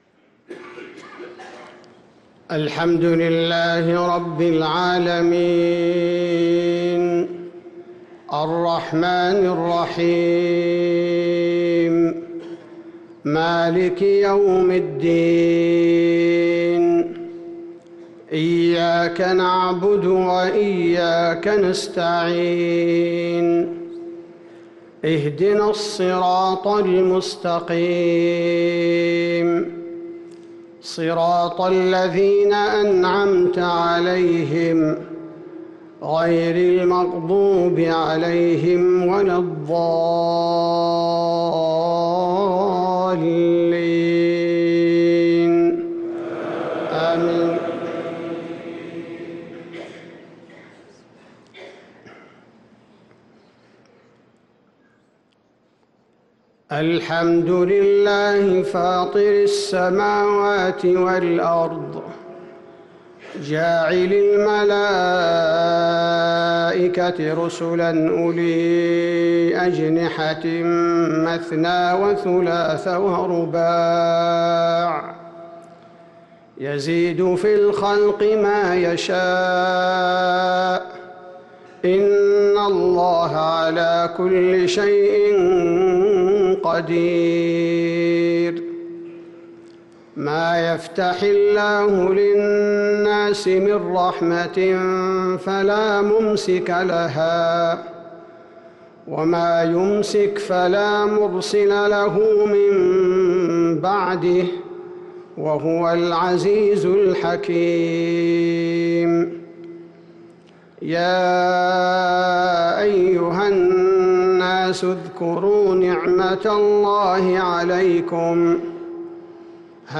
صلاة الفجر للقارئ عبدالباري الثبيتي 13 شوال 1444 هـ
تِلَاوَات الْحَرَمَيْن .